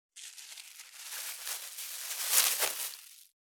634ゴミ袋,スーパーの袋,袋,買い出しの音,ゴミ出しの音,袋を運ぶ音,
効果音